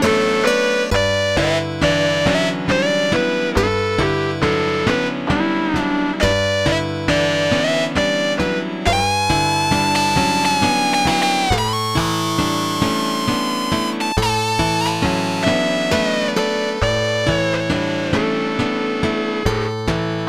EXAMPLE 3: Pitch Modulation and Echo Delay combine to form a guitar amp-like sound:
c700_pmod_guitaresque.mp3